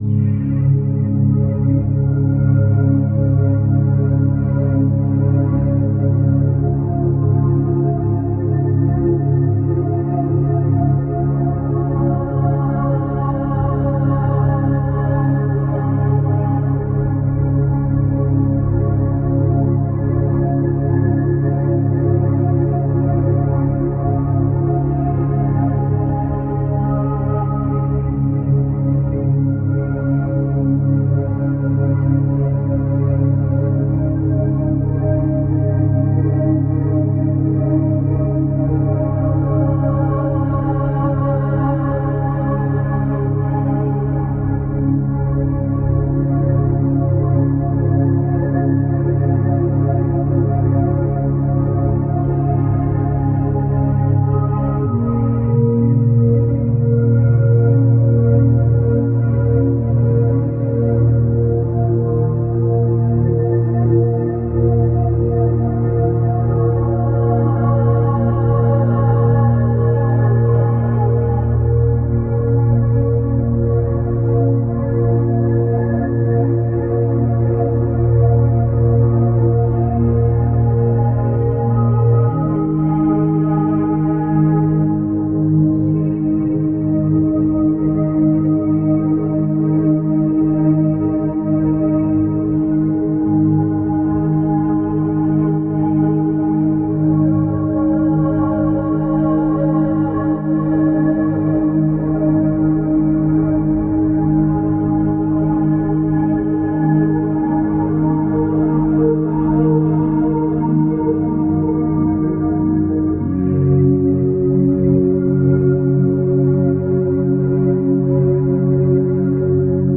tropics.opus